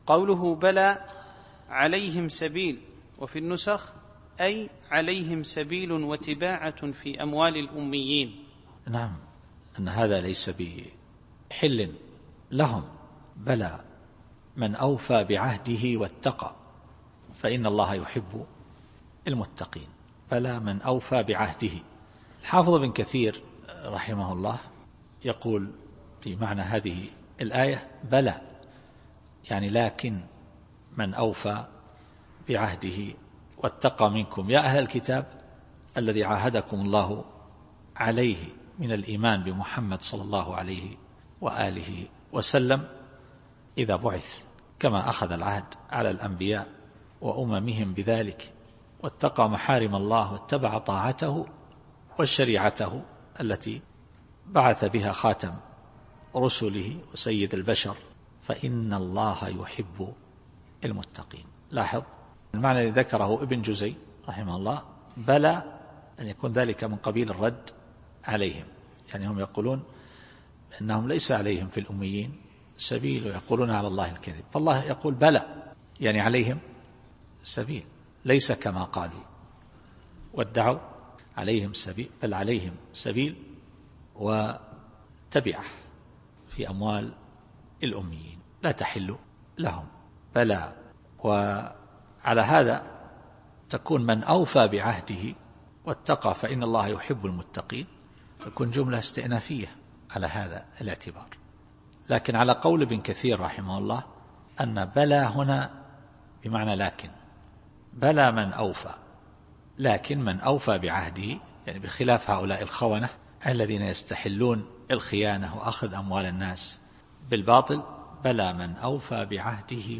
التفسير الصوتي [آل عمران / 76]